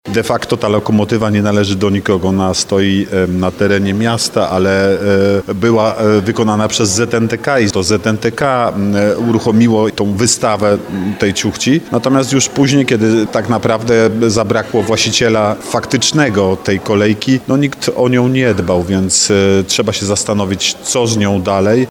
powiedział zastępca prezydenta Nowego Sącza Artur Bochenek